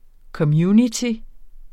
Udtale [ kʌˈmjuniti ]